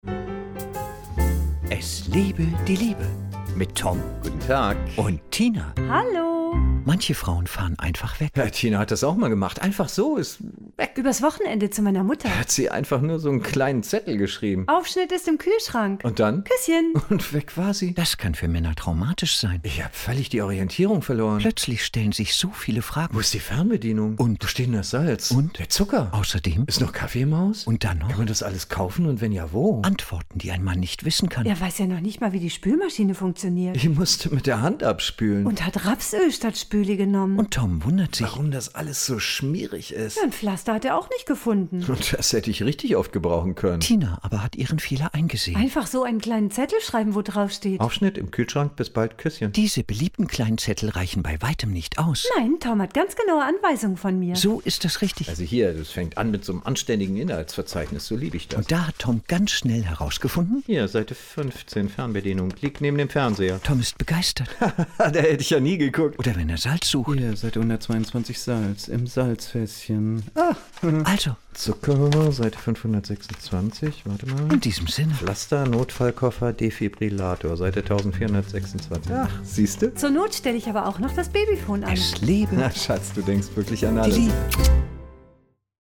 Hörfunkserie
Die Radio-Comedy bekannt vom WDR, NDR, SWR und HR.